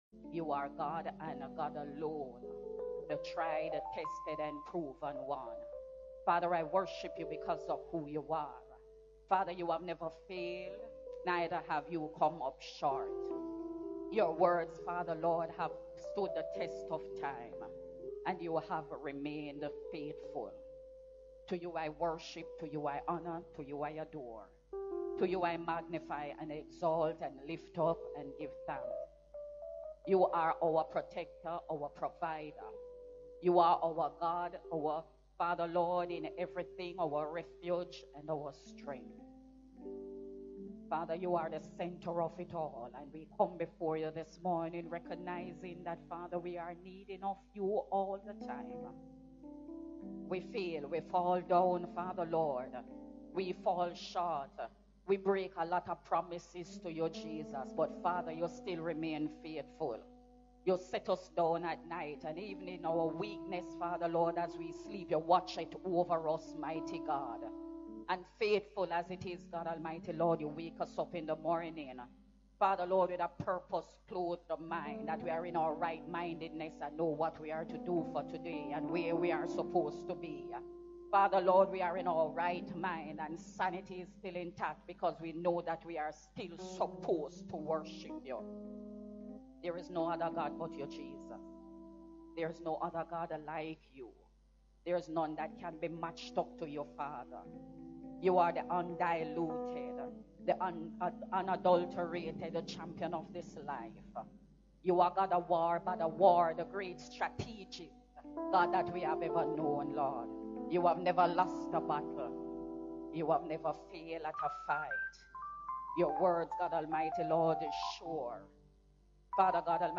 High Praise Worship Service – June 27, 2021 (2nd service)